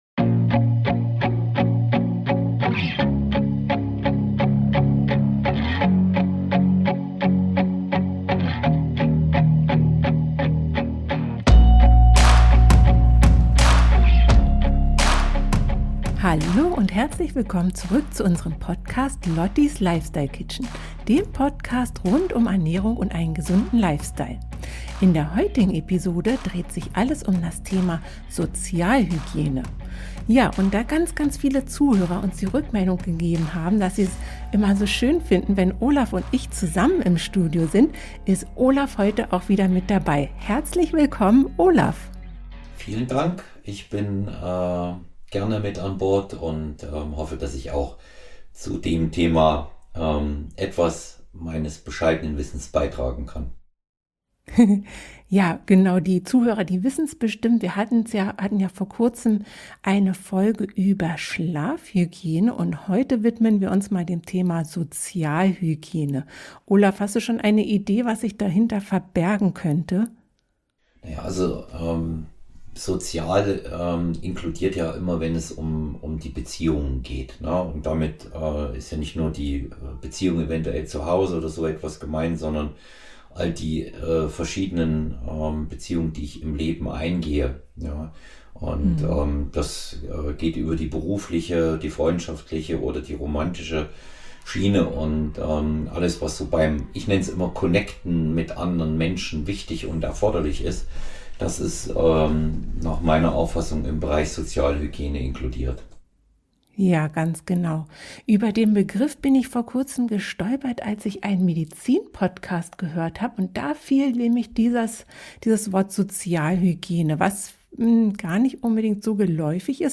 Q&A rund um Testosteron & Hormontherapie 46:04